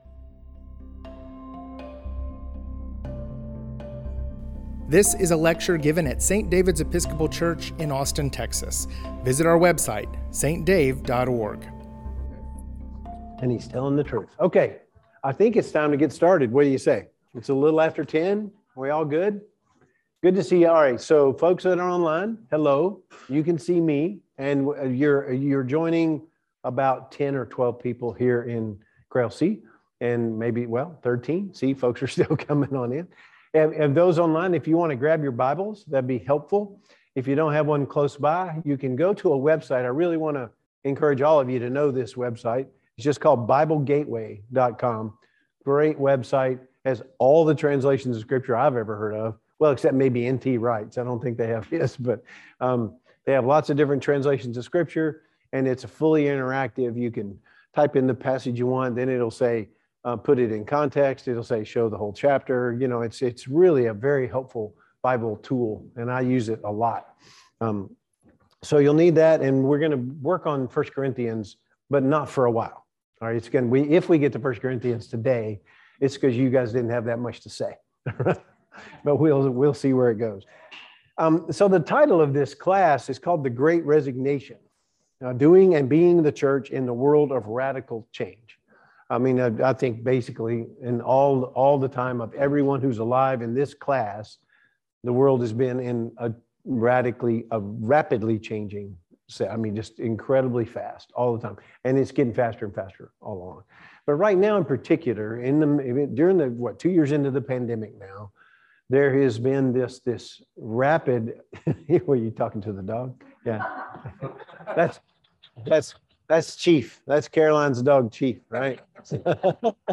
Lecture: The Great Resignation: Doing and Being the Church in a World of Radical Change (Part 1)